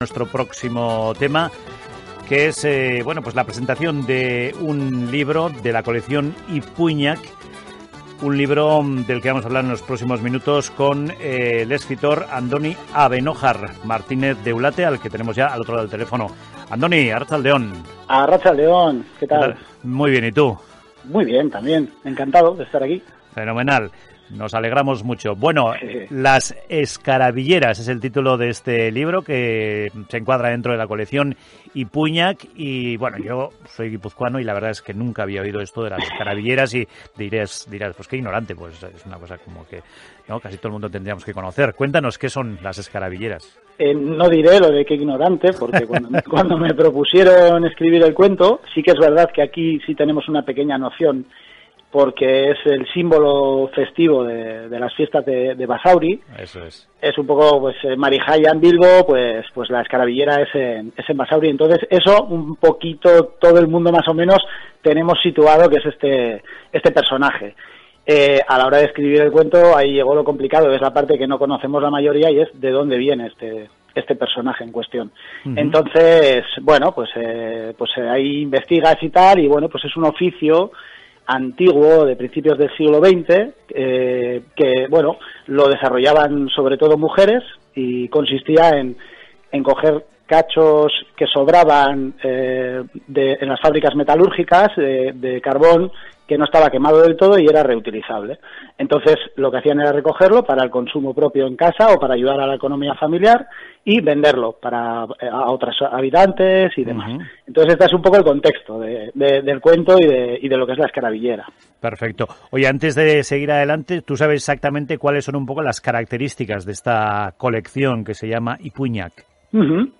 Escuchar entrevista en Onda Vasca:
Entrevista-OndaVasca.mp3